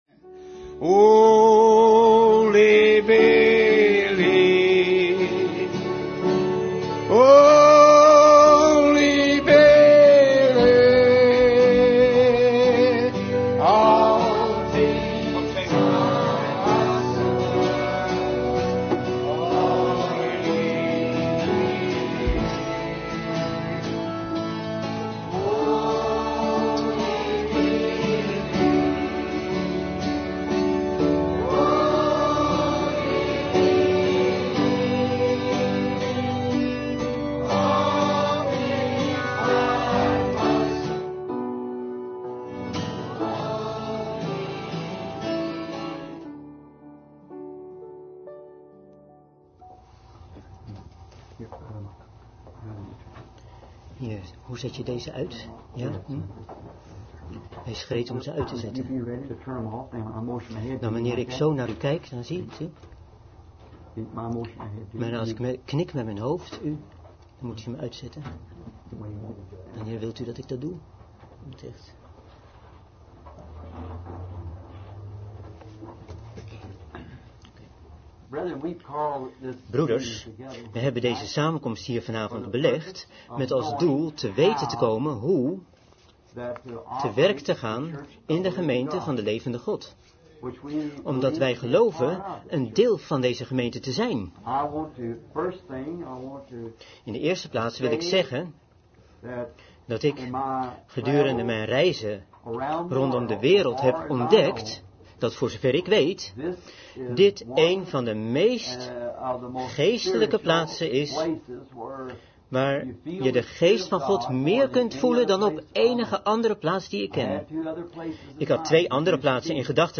Prediking